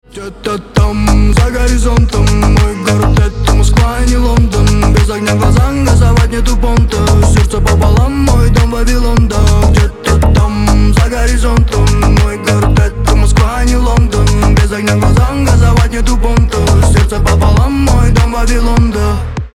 • Качество: 320, Stereo
мужской голос
ритмичные